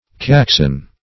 caxon - definition of caxon - synonyms, pronunciation, spelling from Free Dictionary Search Result for " caxon" : The Collaborative International Dictionary of English v.0.48: Caxon \Cax"on\, n. A kind of wig.